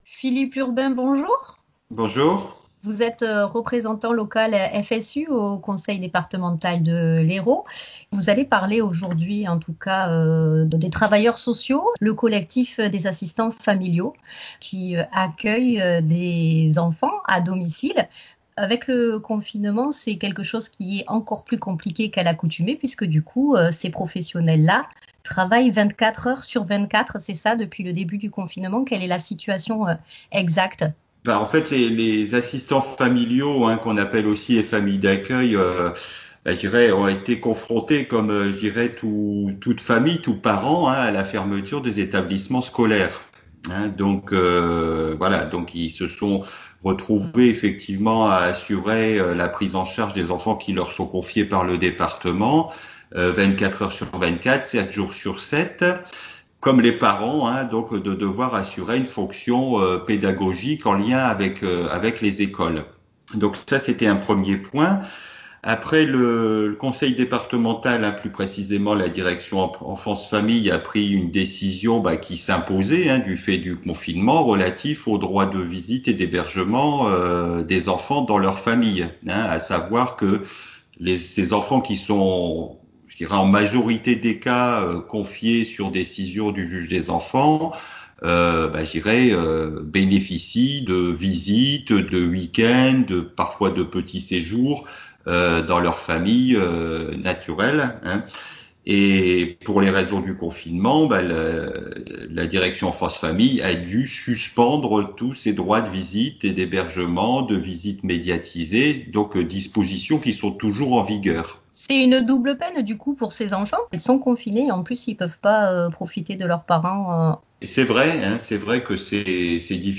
évoque la situation des assistants familiaux sur Radio Clapas